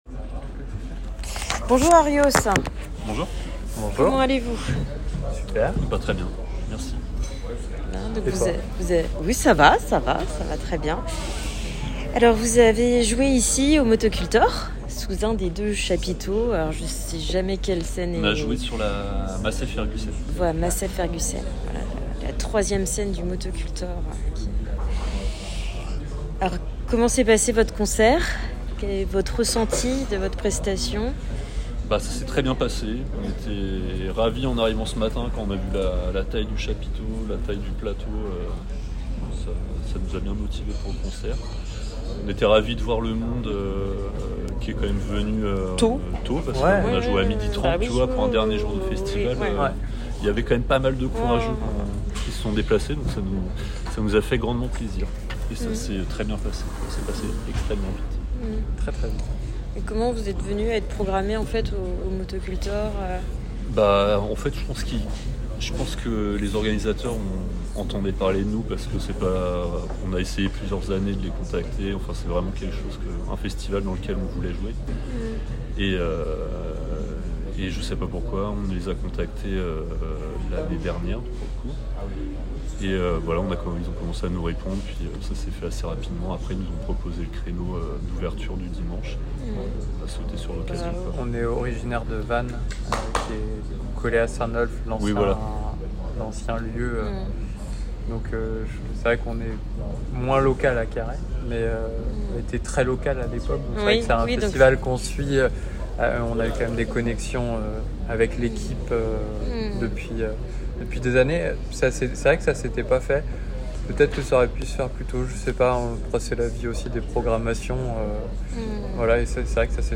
🎙 ITW : Black Bile au Motocultor 2024 👇